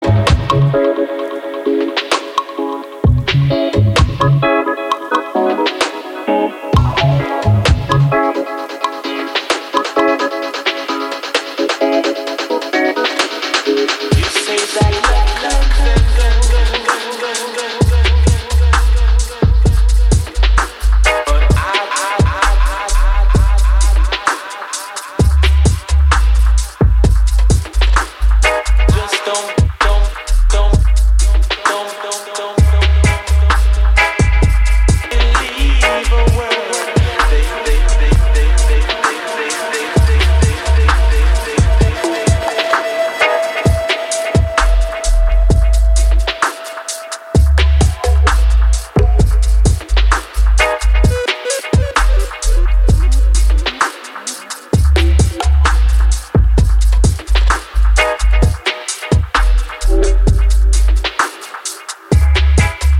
シロップのように甘くヘヴィなローエンド、郷愁に満ちたメロディカ、精霊のように漂うヴォーカル